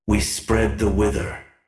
The materials I am posting here are the self-made voice effects for my custom trebuchet unit, "Kukulkan Catapult"!
These voices were recorded by myself and produced using Sovits' voice tone replacement.